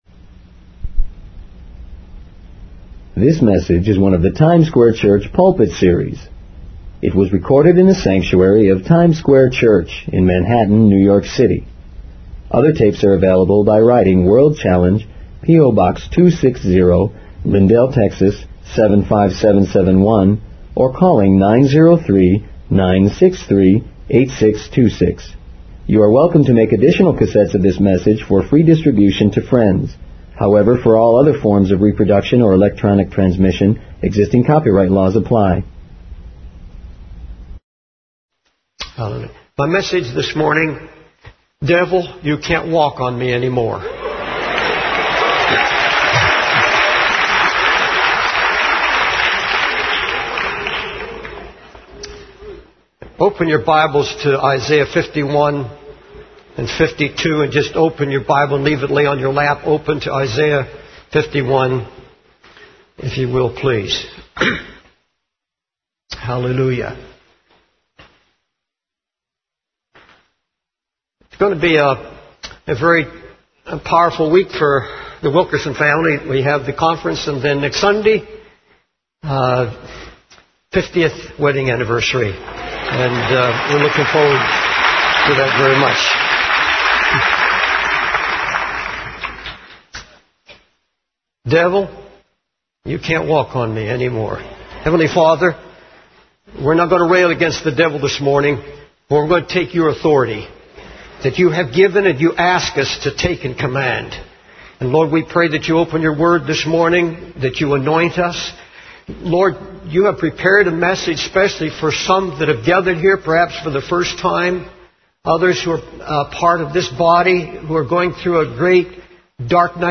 In this sermon, the preacher addresses the issue of God-loving people being oppressed and discouraged by the enemy.